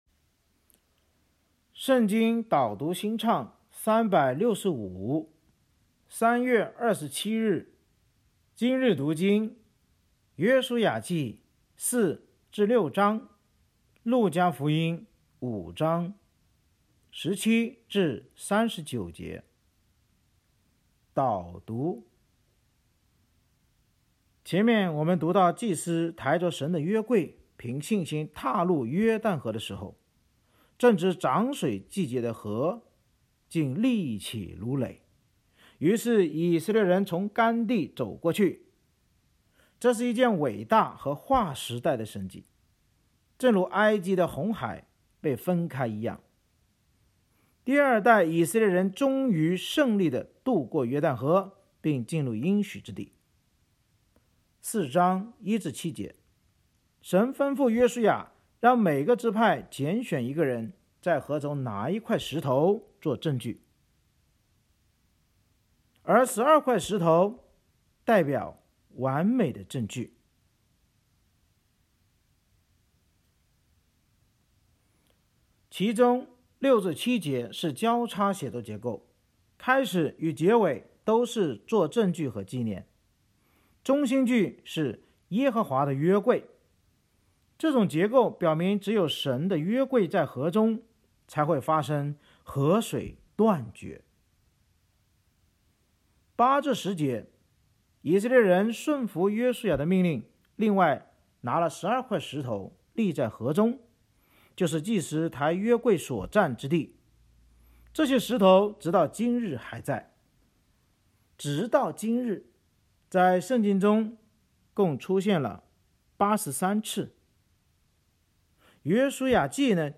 圣经导读&经文朗读 – 03月27日（音频+文字+新歌）